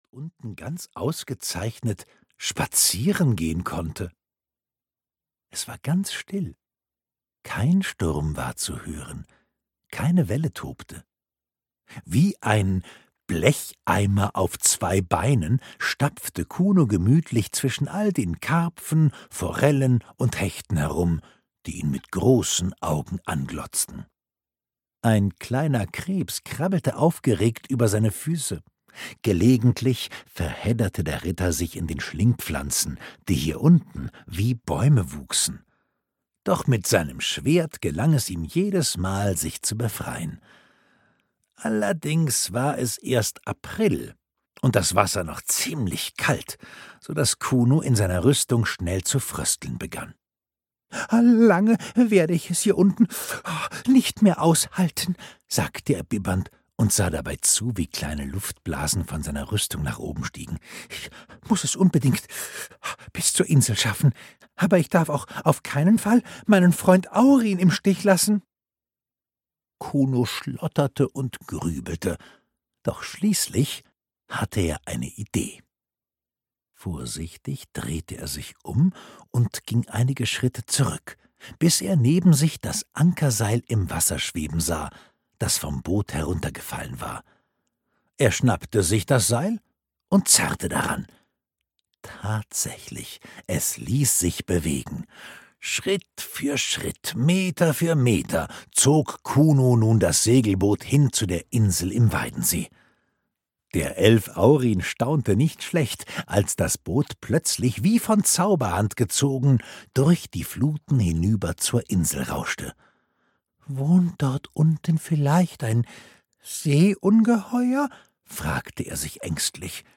Ritter Kuno Kettenstrumpf - Oliver Pötzsch - Hörbuch